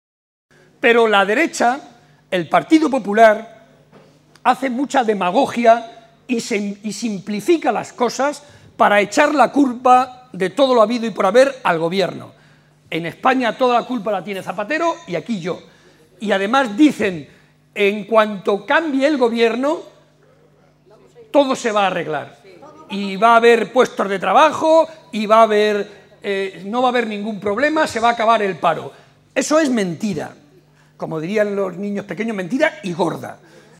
Barreda explicó durante un café-coloquio ante más de 250 personas en Pozuelo de Calatrava que la “brutal” crisis internacional, “que no tiene parangón”, excede de las posibilidades de un gobierno nacional o regional si actúa por sí sólo, por lo que se necesitará de la acción conjunta de la Unión Europea para salir adelante.
Cortes de audio de la rueda de prensa